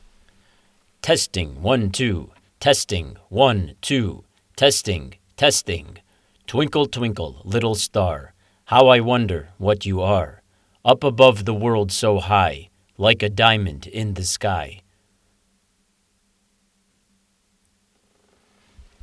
Strange echo in the recording
I’m getting an unusual reverb/echo effect when I record my voice. It happens no matter what room I record in.